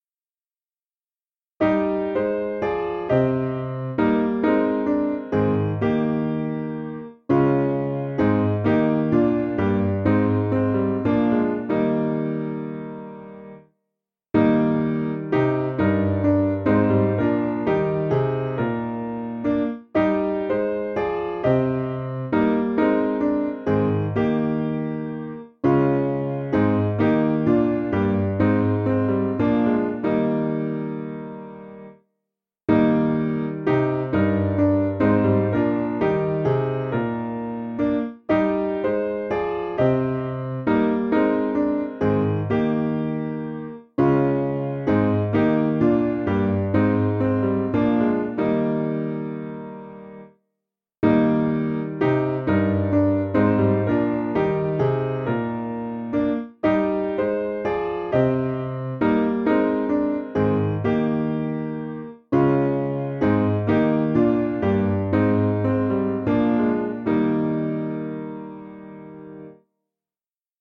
Simple Piano
(CM)   3/Eb 340.3kb